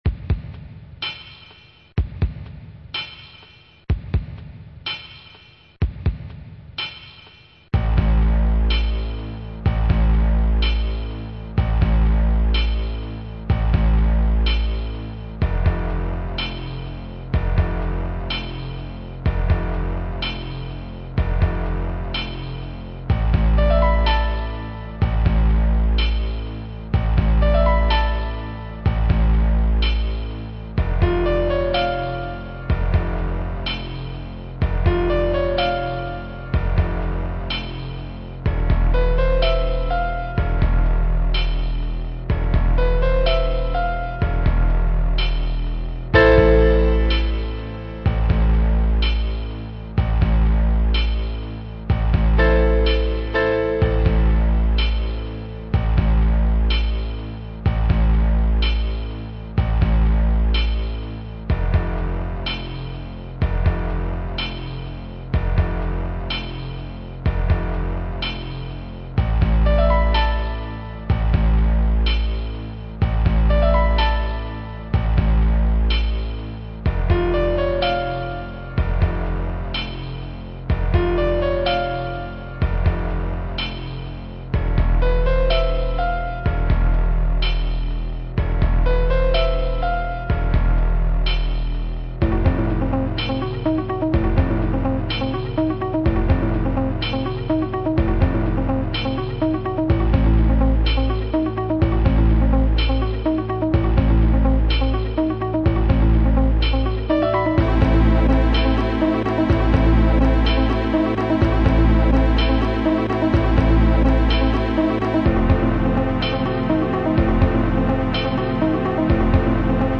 Digital title music
Music written with TCB Tracker